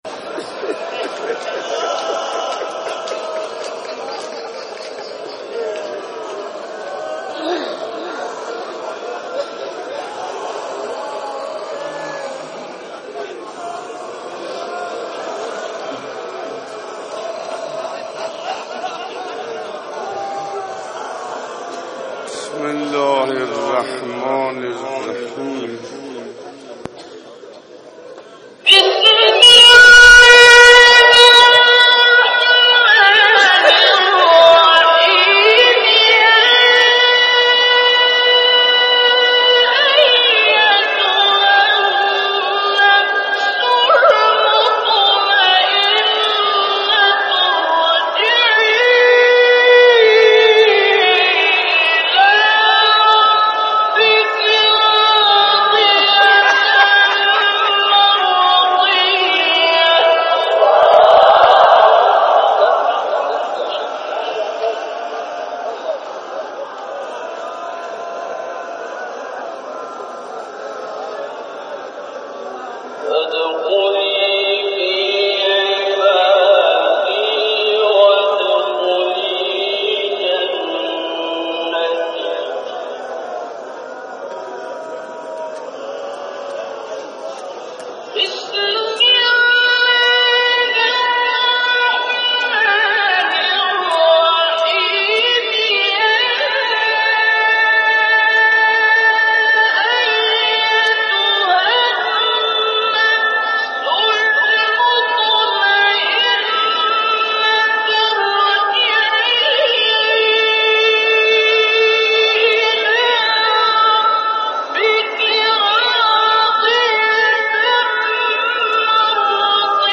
سخنرانی روز دهم محرم 1445 - حسین انصاریان